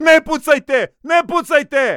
These are the short dialogue lines said by the various units in their original language.
Lots of our dialogue lines feature specific directions (“Under fire” can be expressed panicked, cool, distraught, etc.).
Serb_Chetnik_Panicking_ne_pucajte_ne_pucajte_noeffects